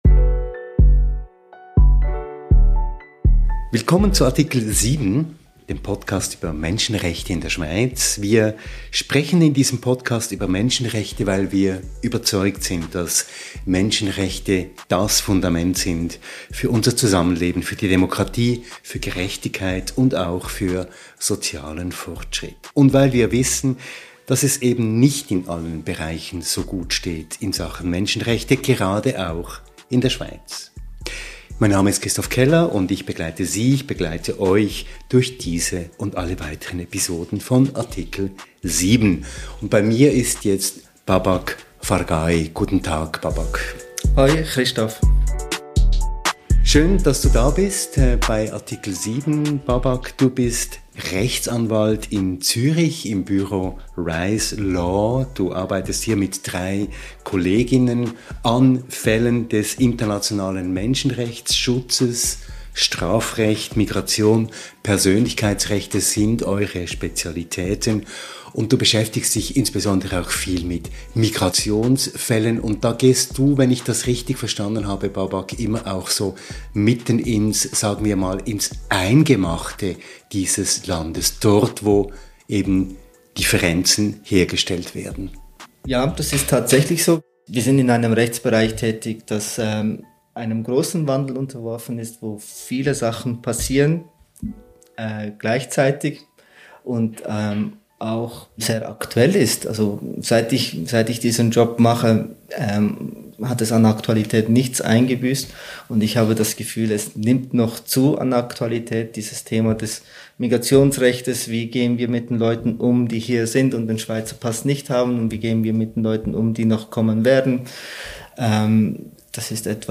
Rechtsanwalt mit Spezialgebiet Migrationsrecht